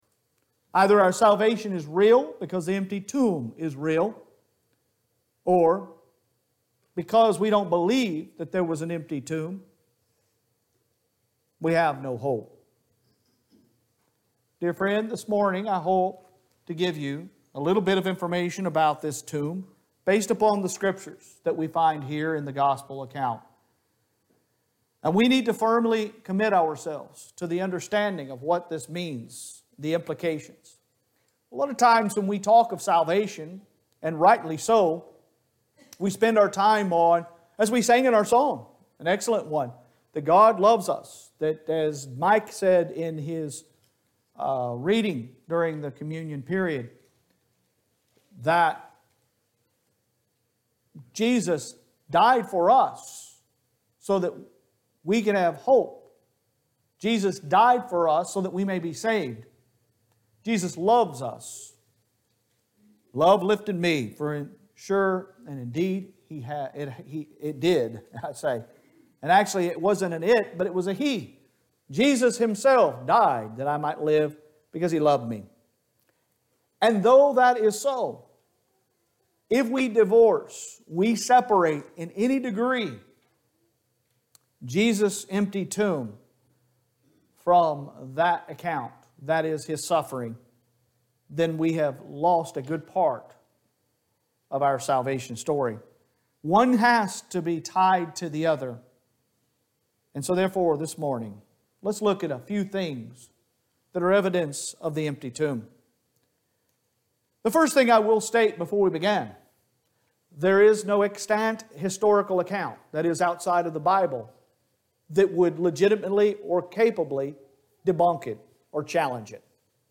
Sun AM Sermon 04.03.22